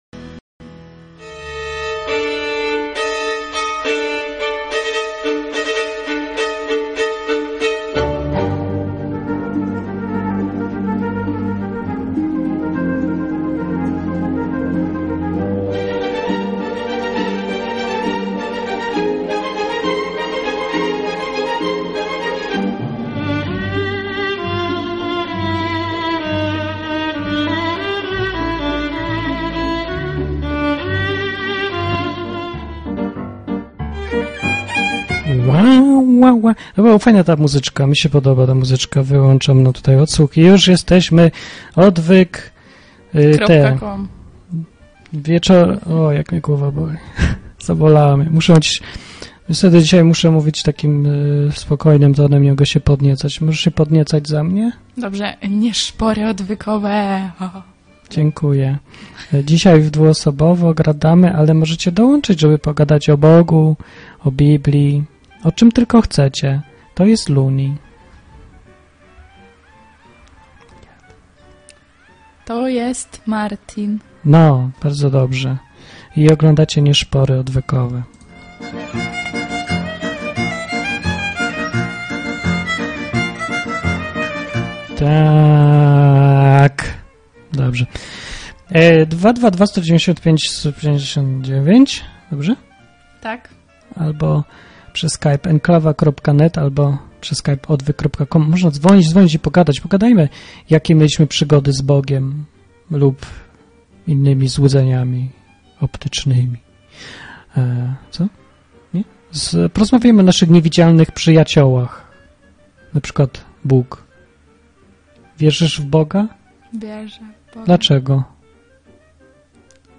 Rozmowy ze słuchaczami na tematy Biblii, Boga, księdza, egzorcyzmów.
To jest mikrofon dynamiczny, więc odległość jest bardzo istotna.